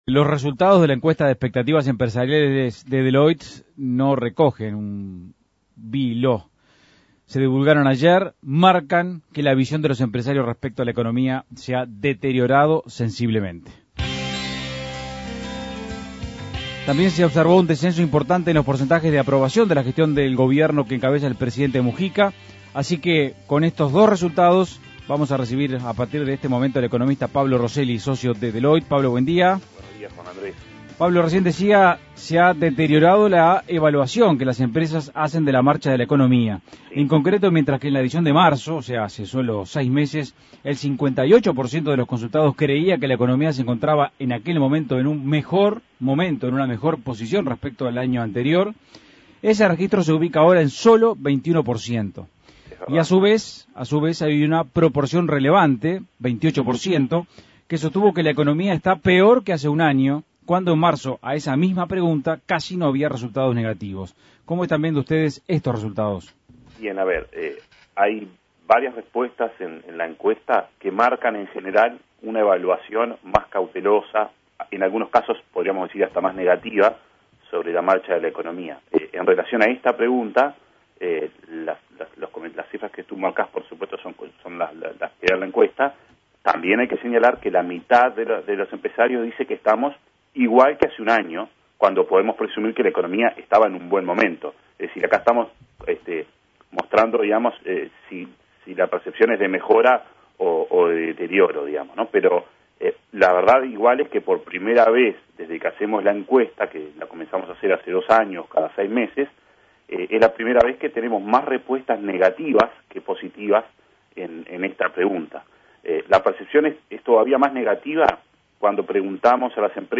Entrevistas Las expectativas empresariales en Uruguay